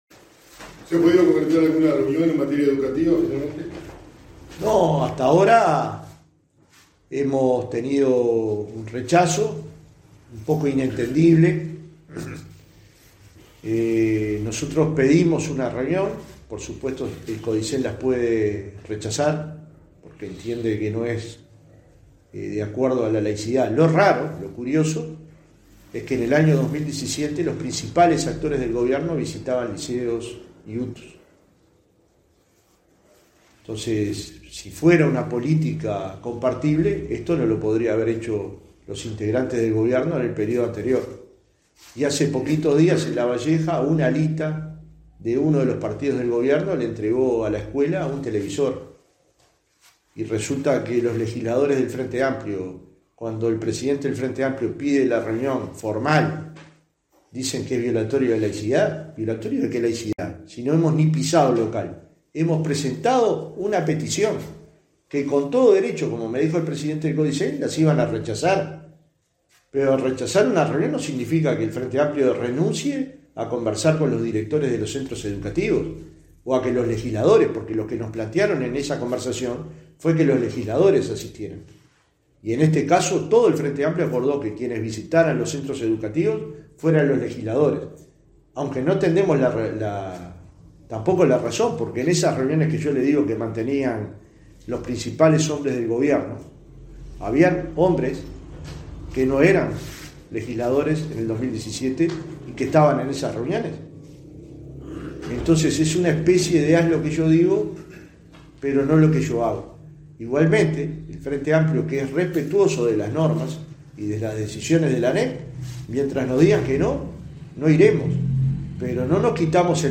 En conferencia de prensa en el departamento de Flores nuestro presidente hizo referencia al tema:
conferencia_de_prensa_Flores-2.mp3